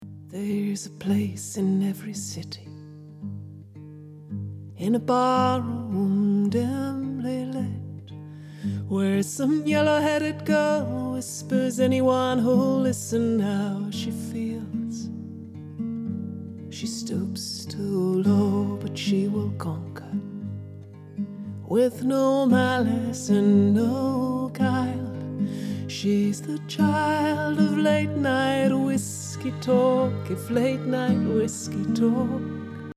dans l'exemple qui suit j'ai donc récorrigé tous les réglages du comp et également baissé le side-chain vers les 2,5kHz :
disons que c'est "moins pire" mais les vagues restent audibles (à cause d'un attack trop court avec un release trop longue)
opto_comp_side2.mp3